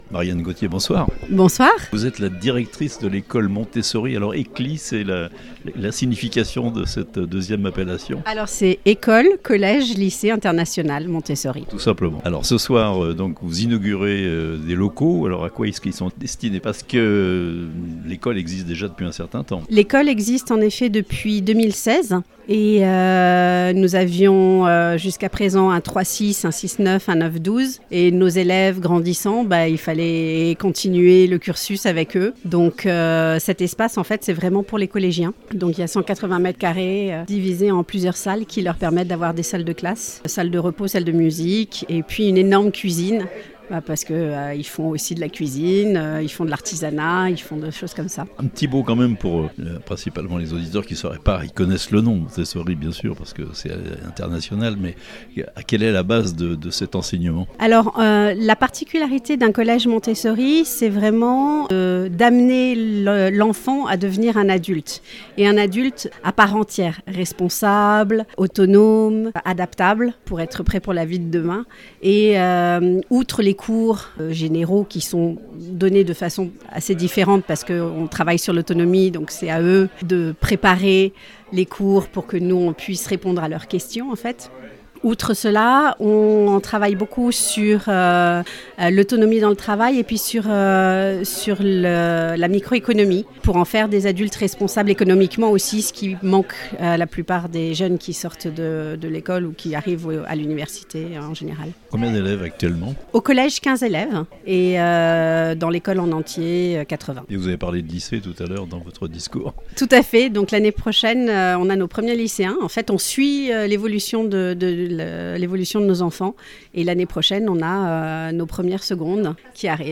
Une classe de collège désormais ouverte à l'Ecole Montessori de Thonon (interview)